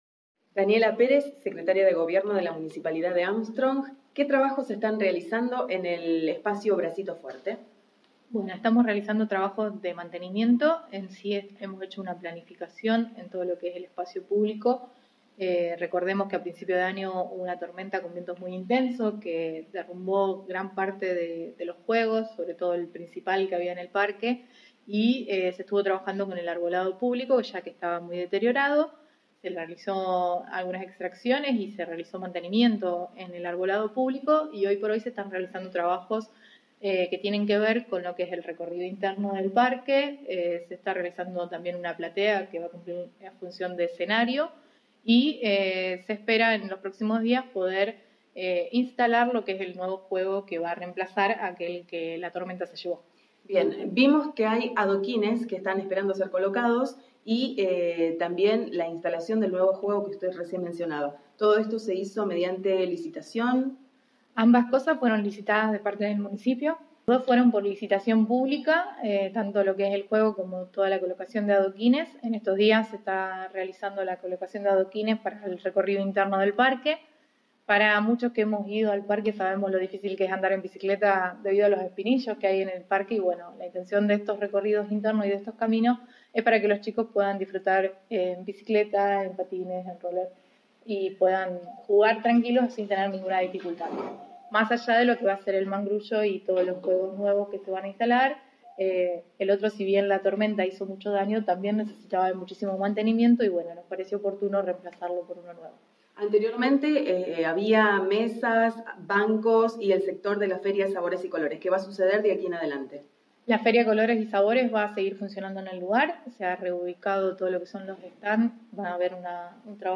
Daniela Perez Secretaria de Gobierno
Nota-con-Daniela-perez-bracito-fuerte.mp3